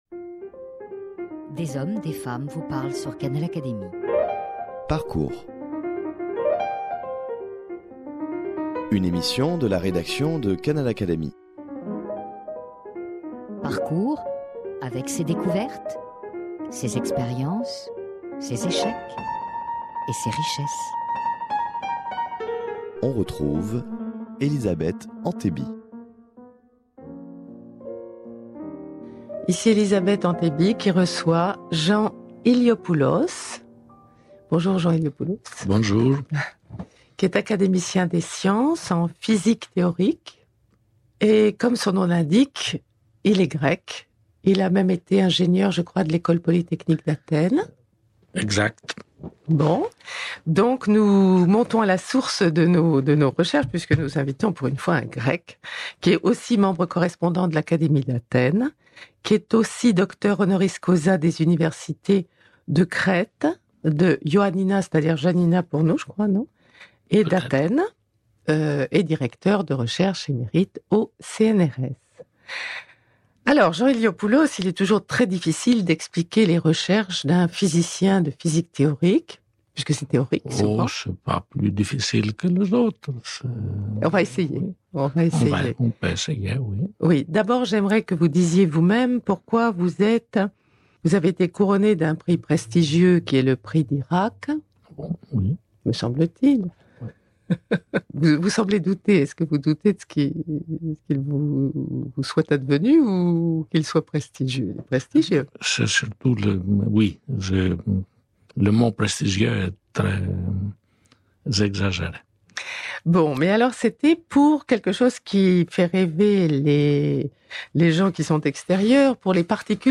Il est le premier à avoir avancé la théorie d’une particule "charmée". Dans cette émission, il est interrogé en tant que scientifique ayant pratiqué les langues anciennes, et pour lui le grec ne fut sans doute pas trop difficile vu ses origines grecques !
Dans cet entretien, il nous parle de ce que pourrait être un « œil grec » sur le monde et surtout déplore la disparition progressive du latin dans l’enseignement.